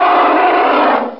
1 channel
REX_ROAR.mp3